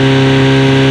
Engines
1 channel